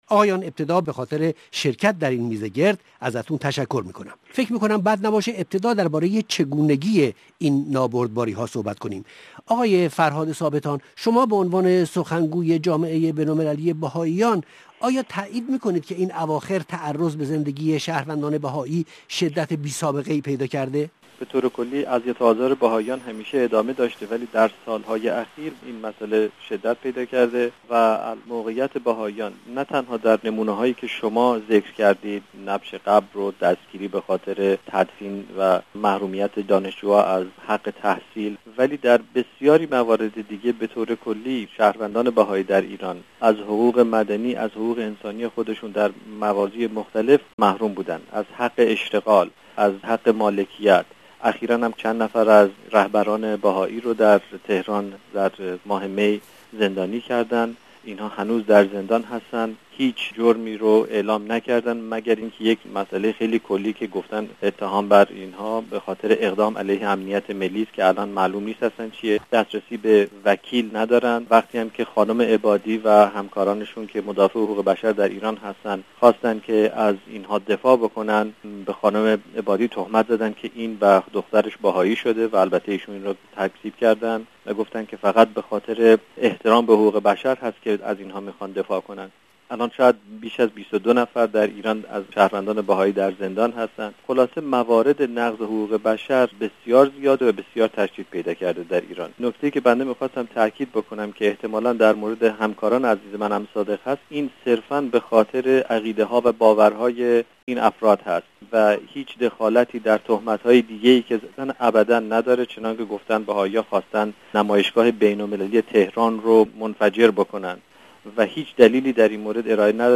در میزگرد زمینه ها و زمانه ها